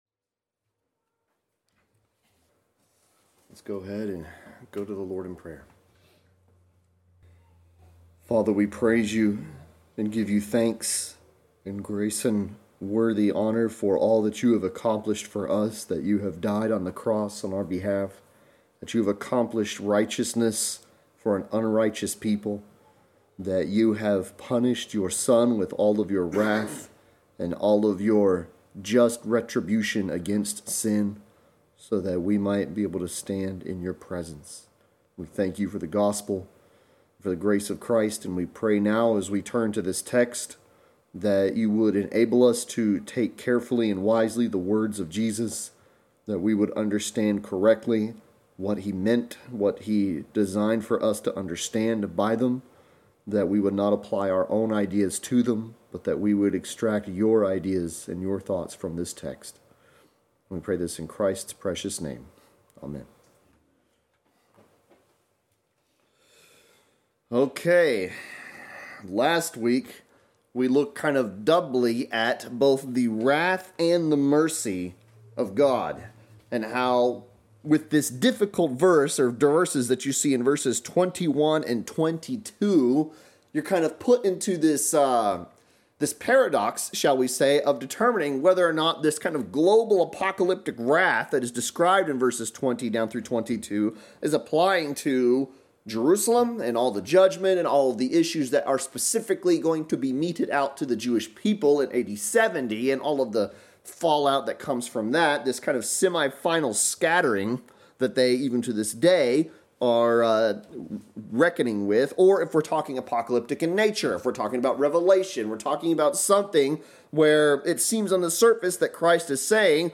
Service Type: Sunday Sermon